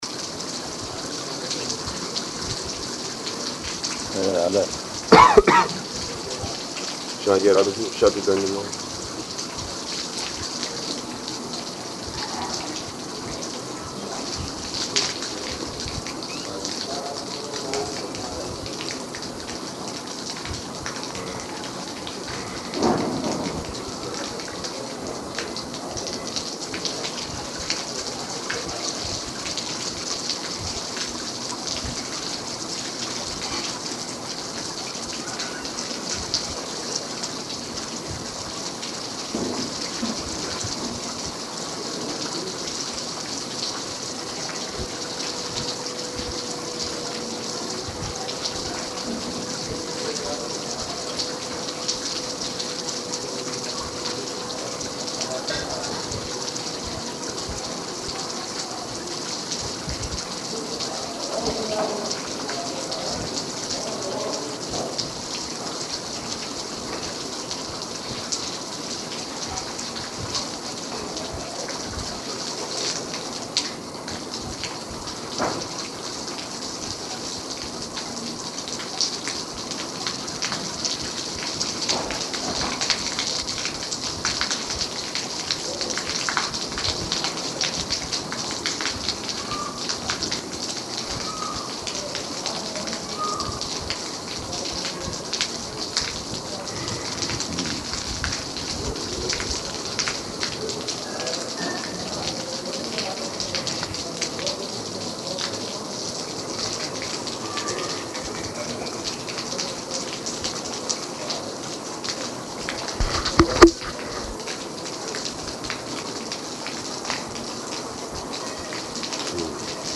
Conversation, "Rascal Editors," and Morning Talk
Conversation, "Rascal Editors," and Morning Talk --:-- --:-- Type: Conversation Dated: June 22nd 1977 Location: Vṛndāvana Audio file: 770622R1.VRN.mp3 Prabhupāda: Where are others?